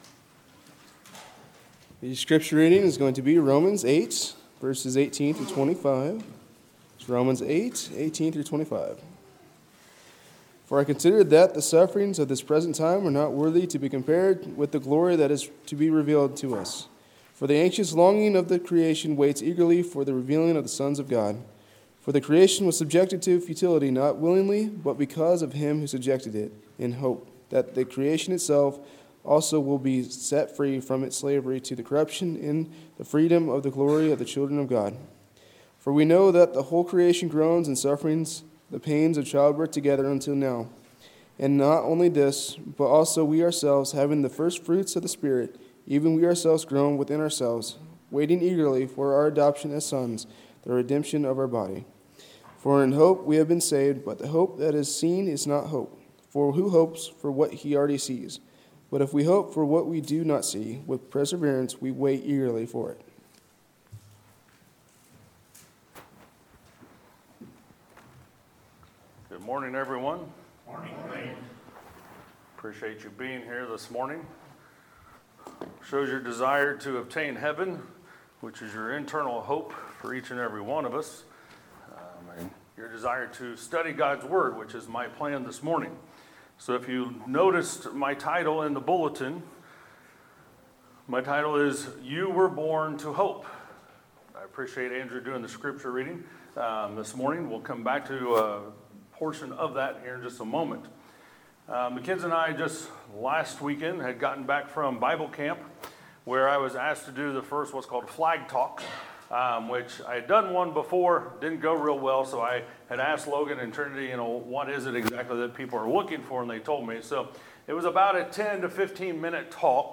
Sermons, July 14, 2019